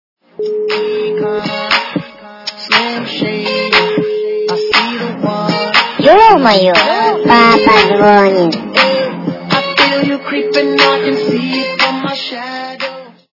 » Звуки » Смешные » Ё-маё! - Папа звонит
При прослушивании Ё-маё! - Папа звонит качество понижено и присутствуют гудки.